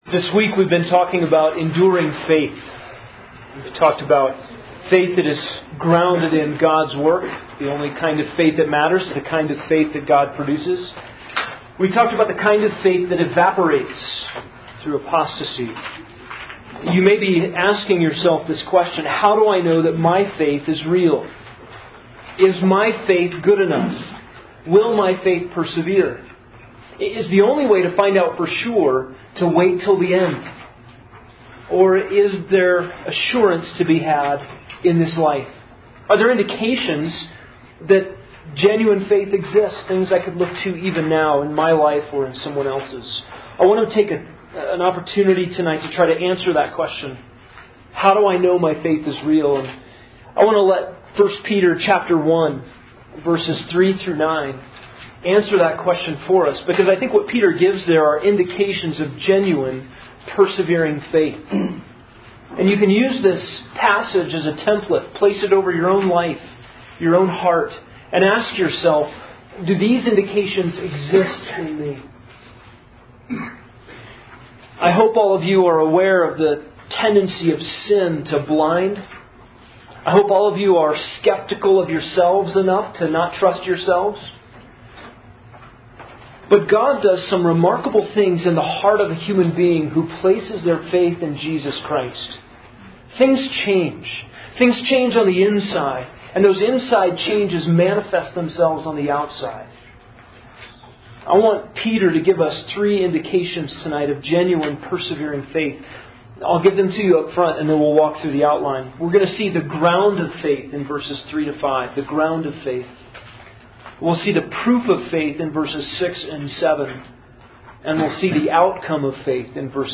College/Roots Roots Winter Retreat - 2014 Audio ◀ Prev Series List Previous 2.